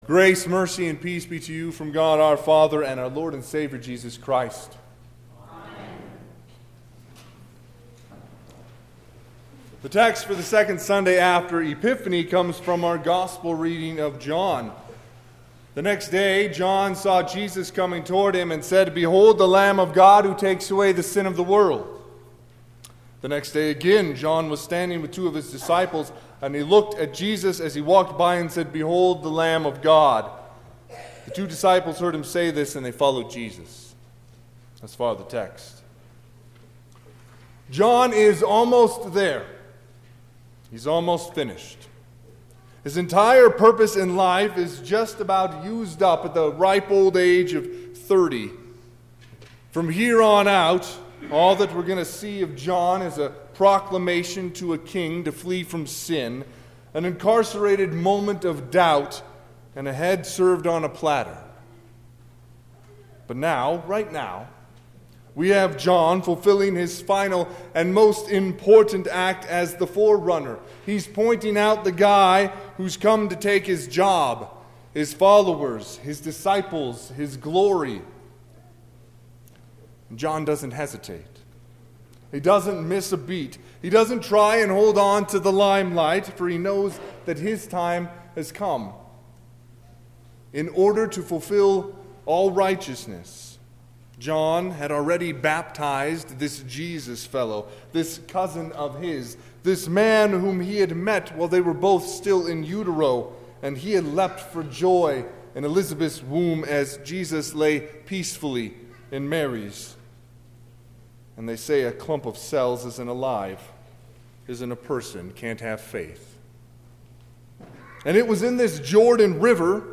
Sermon - 1/19/2020 - Wheat Ridge Lutheran Church, Wheat Ridge, Colorado
Second Sunday after the Epiphany